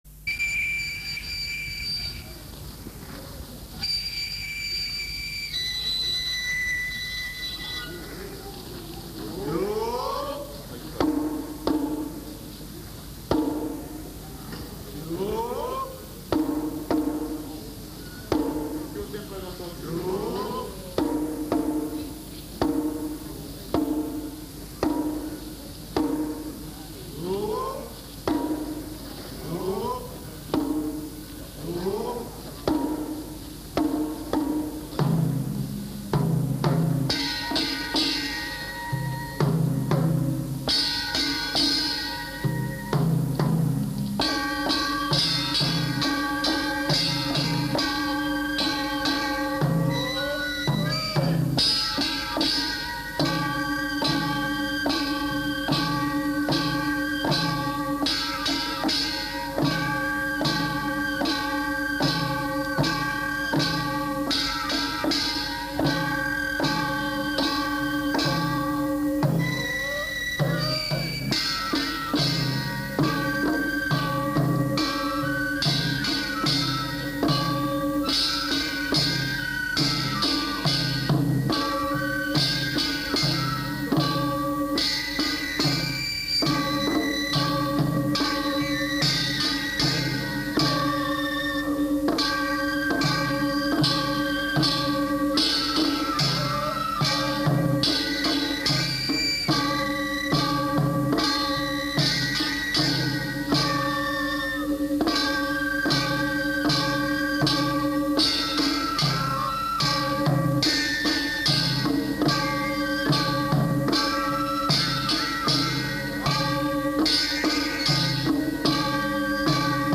関東一の祇園 熊谷うちわ祭